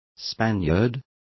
Complete with pronunciation of the translation of Spaniard.